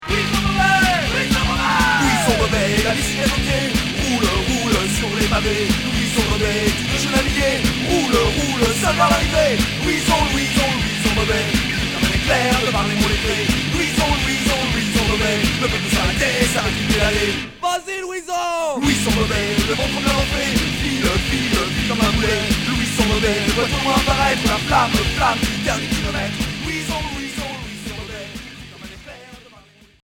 Punk Alternatif